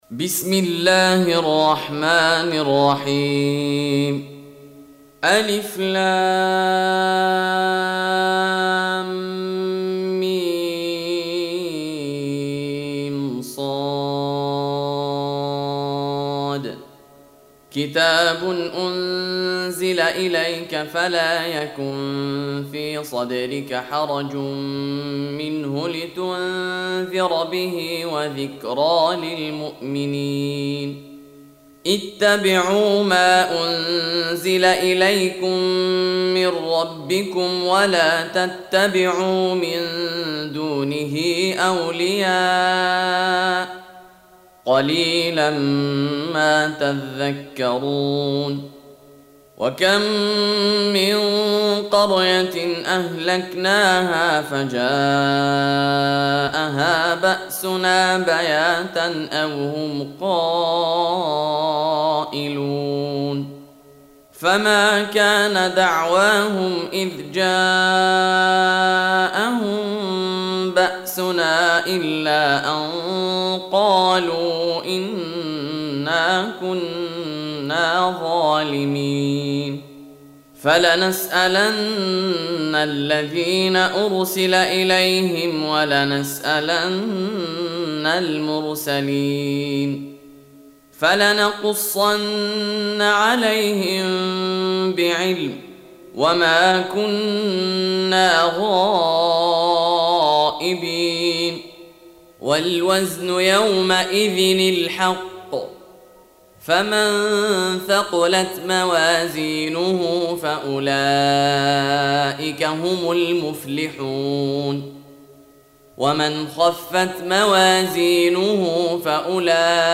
7. Surah Al-A'r�f سورة الأعراف Audio Quran Tarteel Recitation
Surah Repeating تكرار السورة Download Surah حمّل السورة Reciting Murattalah Audio for 7. Surah Al-A'r�f سورة الأعراف N.B *Surah Includes Al-Basmalah Reciters Sequents تتابع التلاوات Reciters Repeats تكرار التلاوات